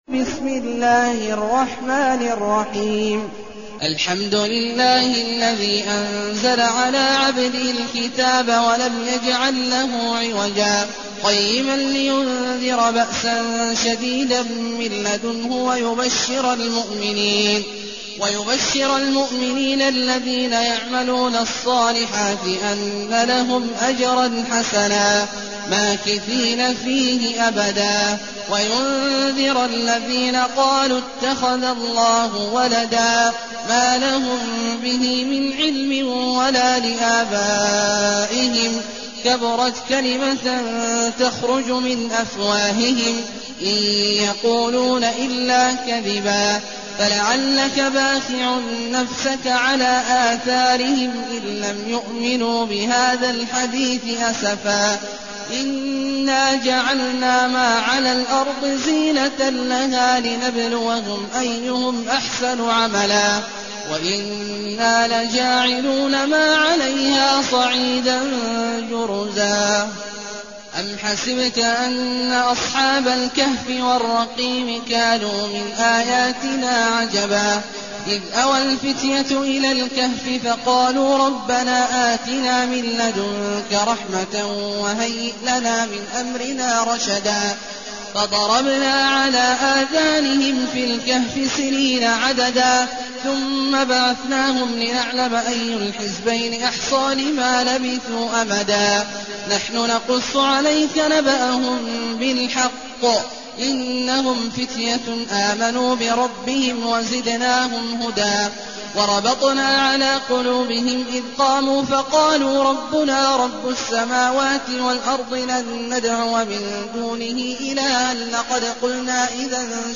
المكان: المسجد النبوي الشيخ: فضيلة الشيخ عبدالله الجهني فضيلة الشيخ عبدالله الجهني الكهف